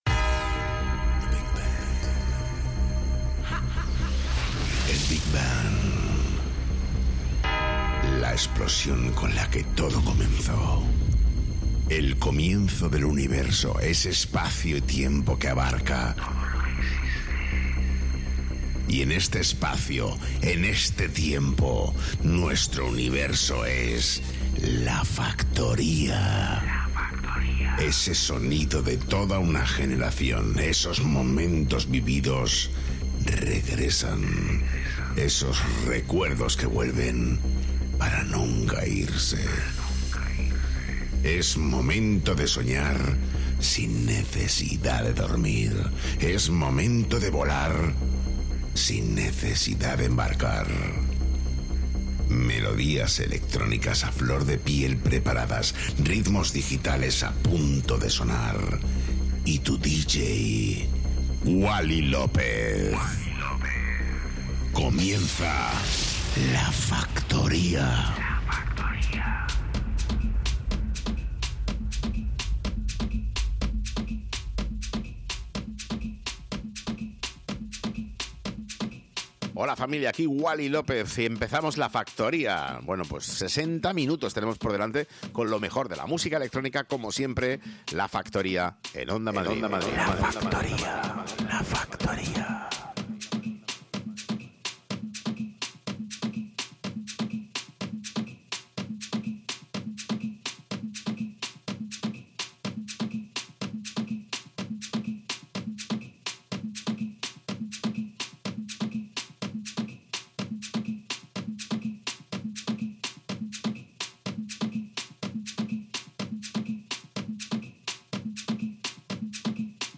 Wally López, el DJ más internacional de Madrid retoma “La Factoría” para todos los madrileños a través de Onda Madrid.